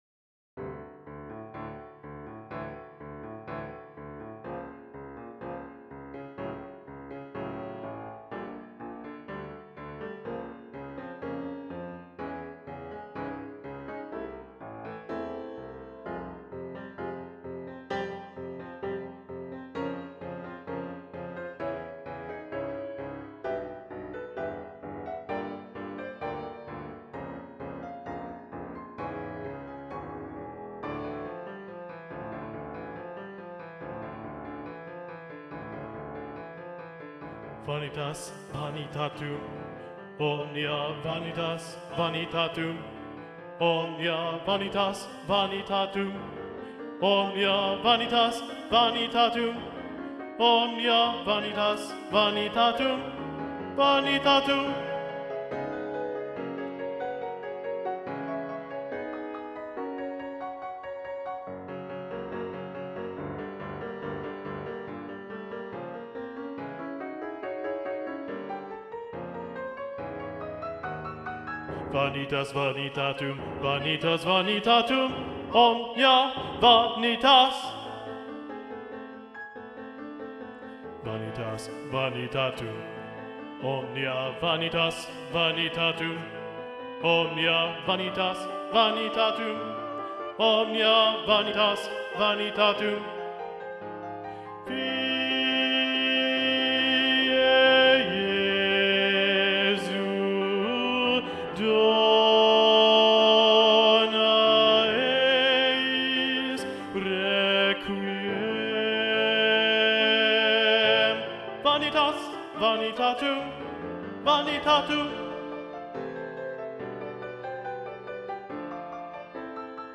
Video Only: Vanitas Vanitatum - Bass 2 Predominant